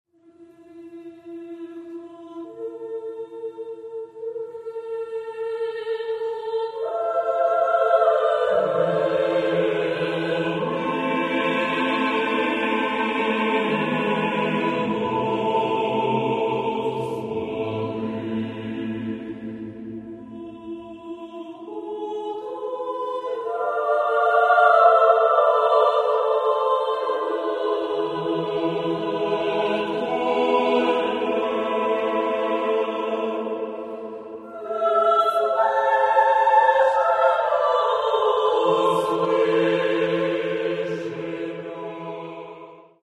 - Хорові концерти: